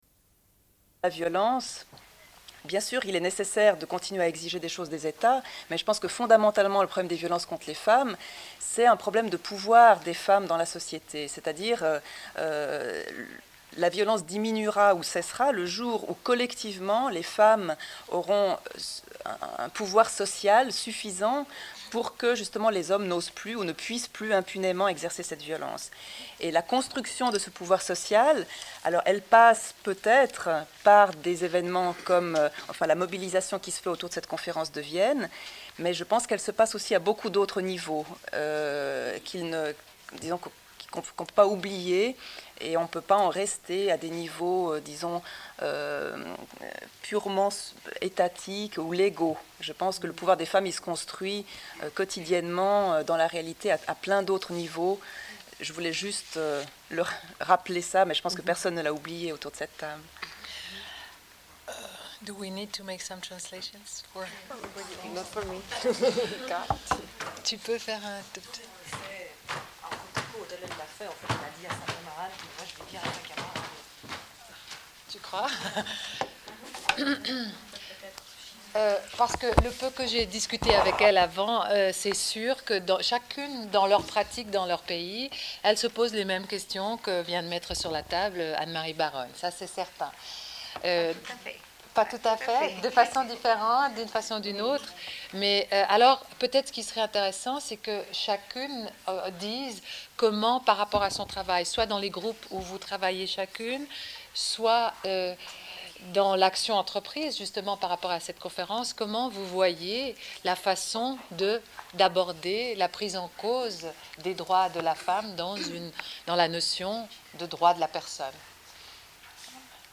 Une cassette audio, face B29:09